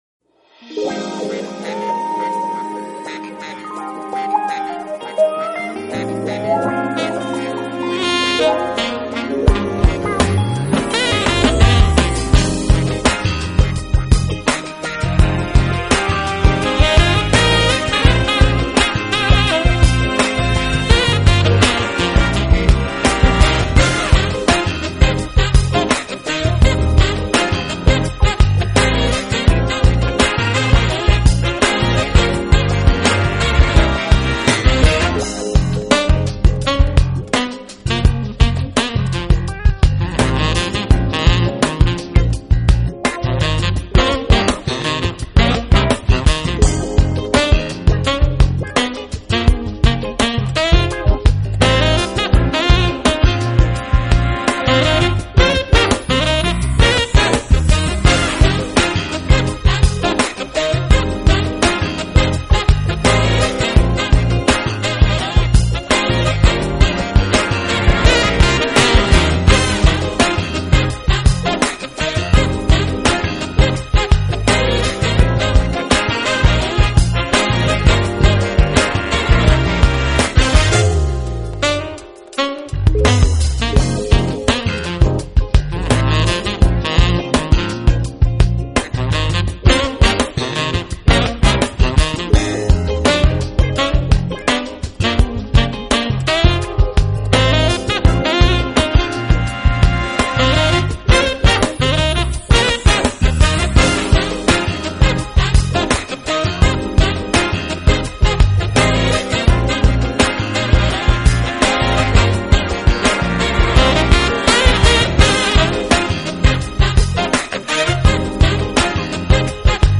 GENRE : Jazz
guaranteed to get your head bobbin'.